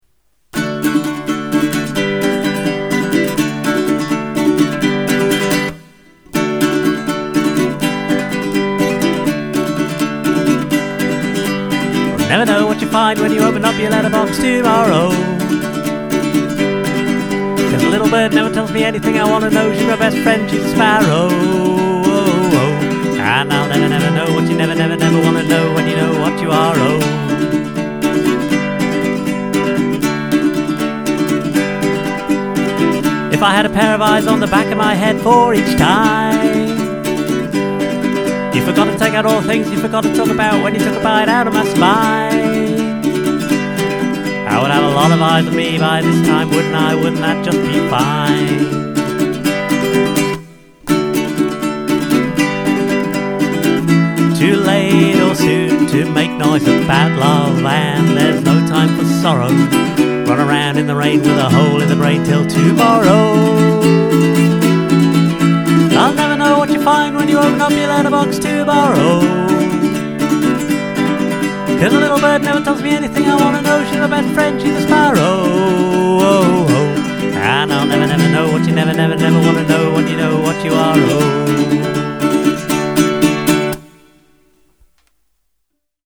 Yes, it really is meant to go this fast.
That is a ridiculously fast lyrical line to sing.